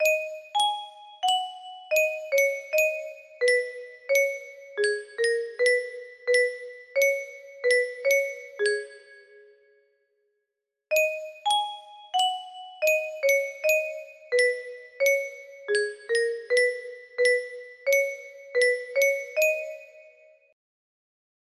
Altatódal music box melody